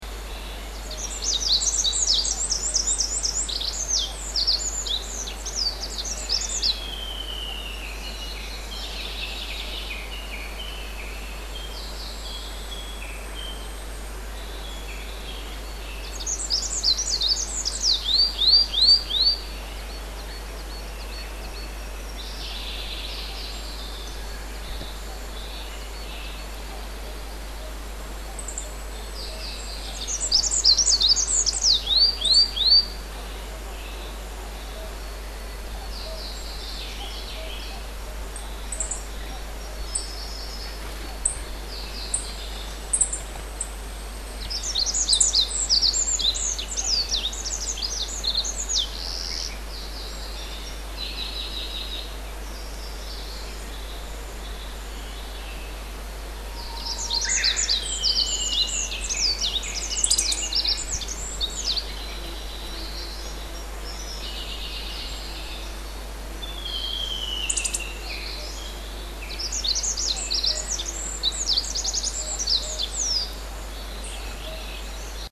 【 ビンズイ 】
全長約16センチほどでスズメ目セキレイ科の鳥で、囀りは複雑で すがきれいな声で「ピンピンツィーツィー」と鳴くのが和名「ビンズイ」の語源で、漢字では「便追」と書きますが、その由来はさだかではありません。下の画像 をクリックするとビンズイの鳴き声が聴けます。